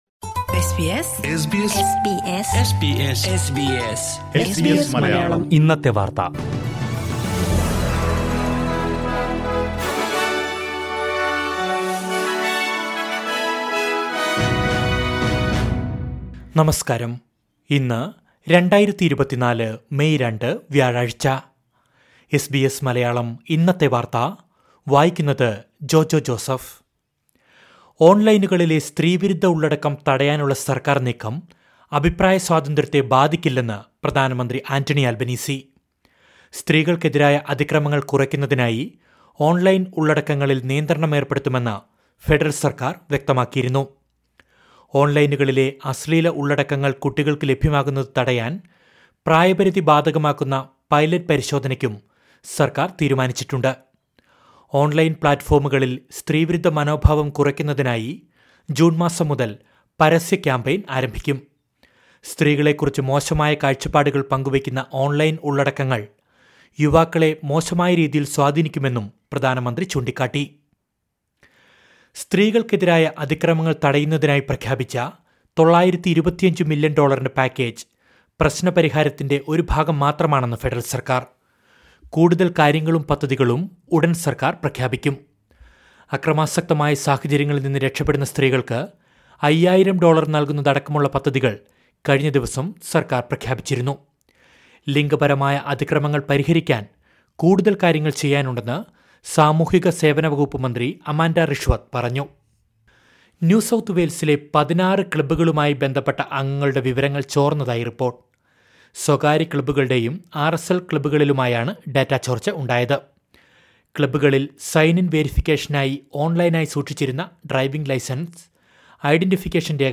2024 മെയ് രണ്ടിലെ ഓസ്‌ട്രേലിയയിലെ ഏറ്റവും പ്രധാന വാര്‍ത്തകള്‍ കേള്‍ക്കാം...